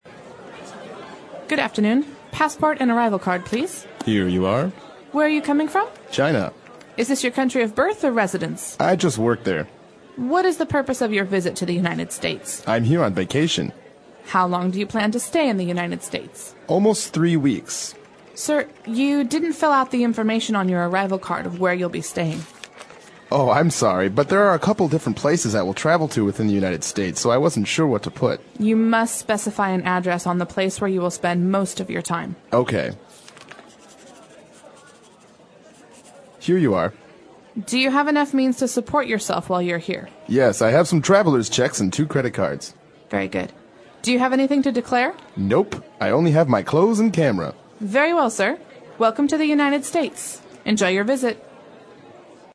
外教讲解纯正地道美语|第331期:海关检查